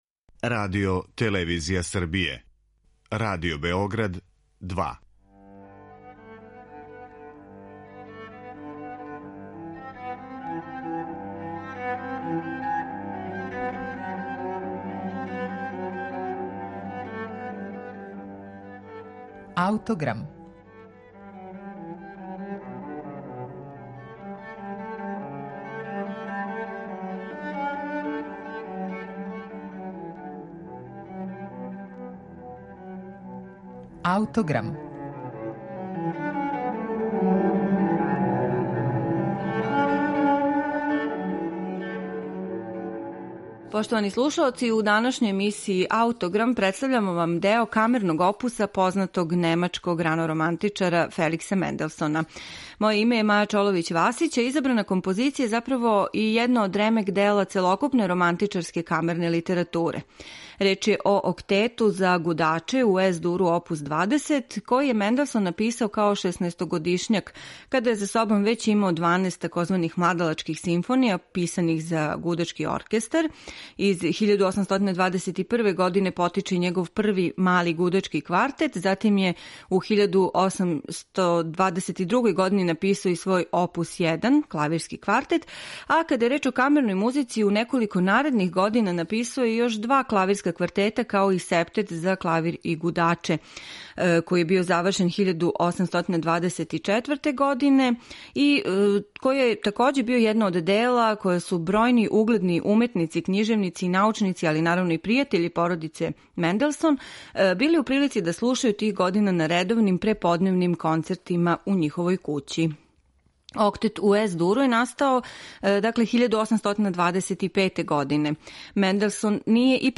Једно од ремек-дела целокупне романтичарске камерне литературе – Октет за гудаче у Ес-дуру оп. 20 немачког композитора Феликса Менделсона, из 1825. године, слушаћете данас у извођењу чланова Краљевског гудачког квартета и квартета Софос.